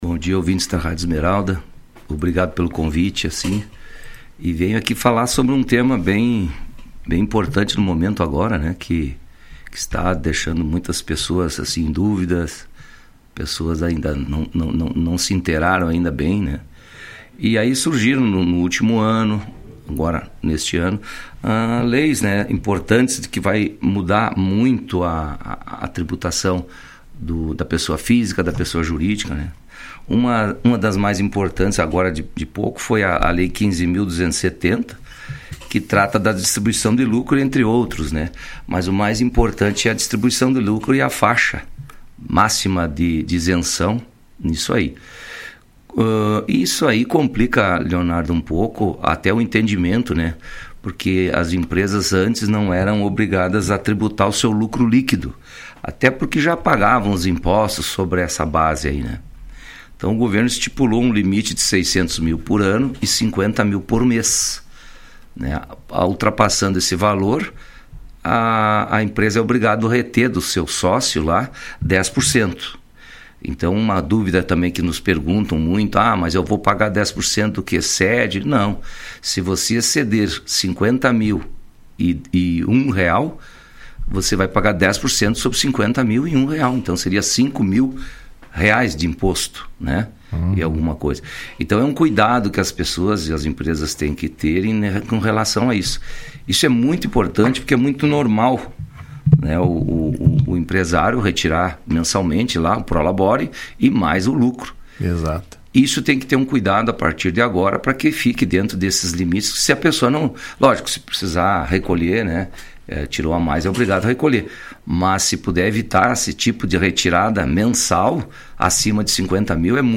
Contador explica como vai funcionar reforma tributária para pessoas jurídicas e físicas